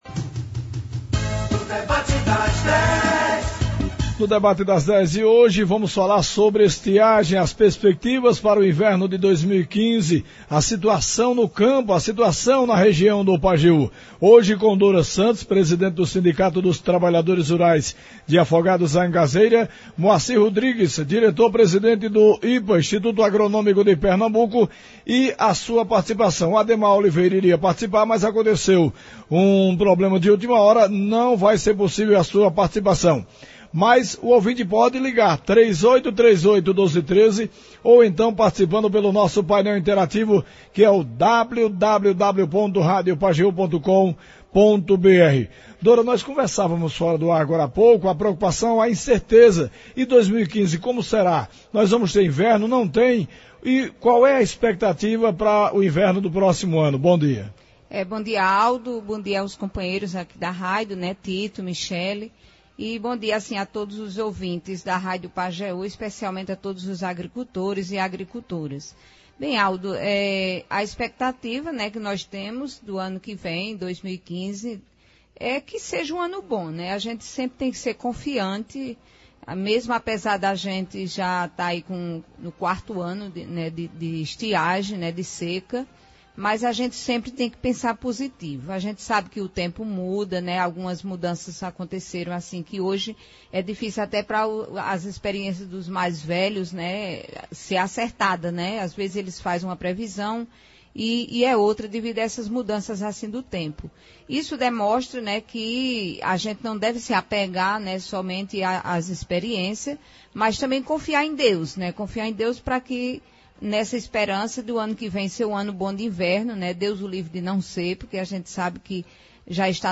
Ouça abaixo como foi esse debate: